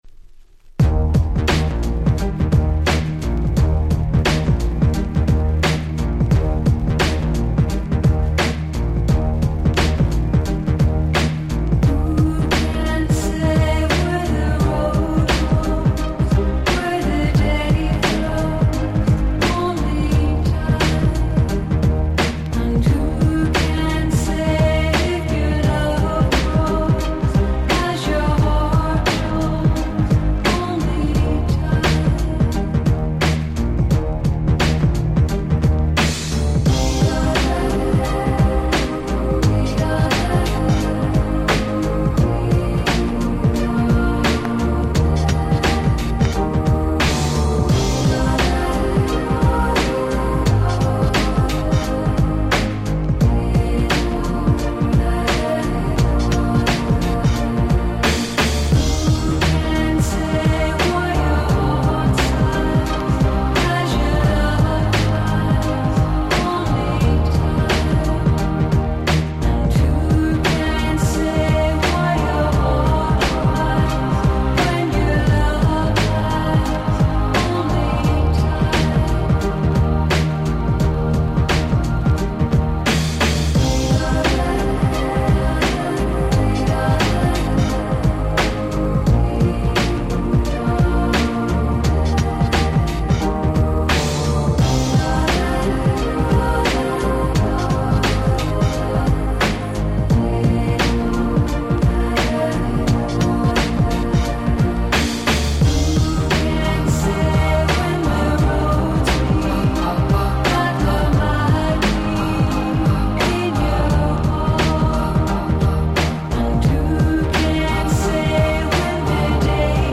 Ground Beatのリズムを下に敷くだけであら不思議、壮大感3割り増しなのです！！